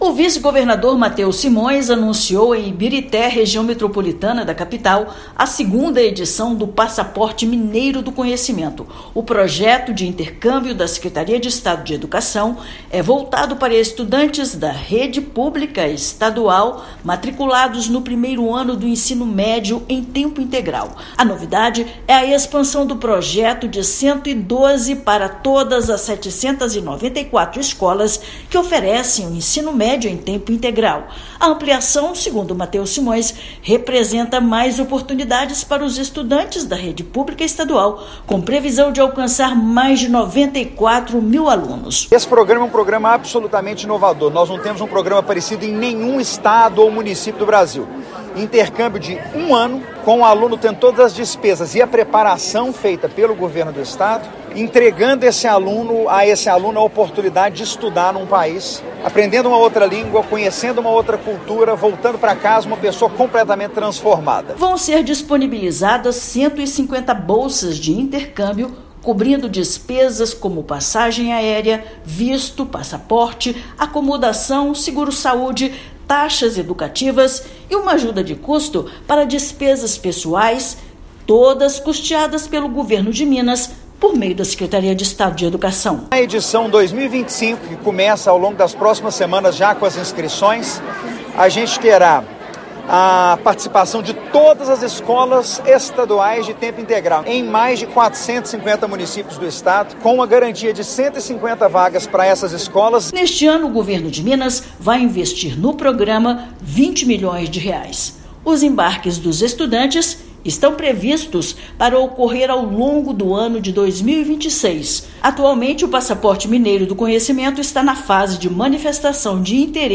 [RÁDIO] Governo de Minas amplia Passaporte Mineiro do Conhecimento para todas as 794 escolas de Ensino Médio em Tempo Integral do estado
Segunda edição dará oportunidade para 150 estudantes cursarem o 2° ano do ensino médio no exterior. Ouça matéria de rádio.